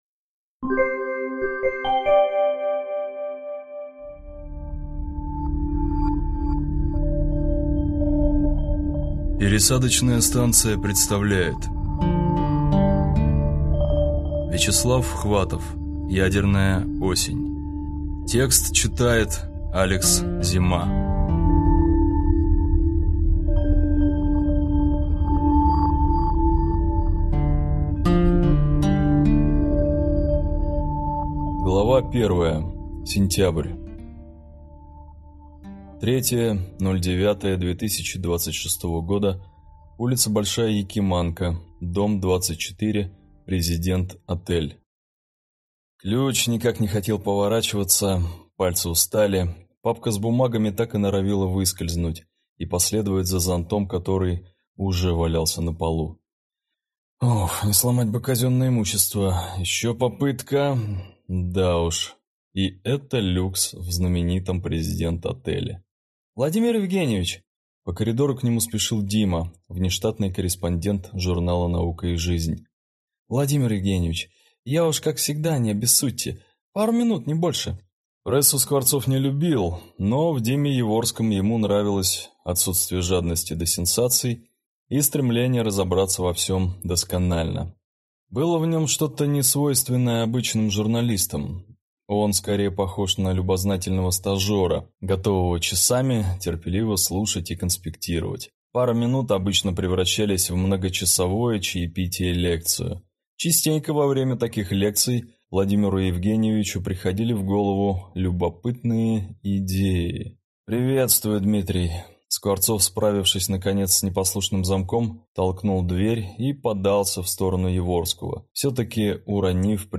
Аудиокнига Ядерная осень | Библиотека аудиокниг
Прослушать и бесплатно скачать фрагмент аудиокниги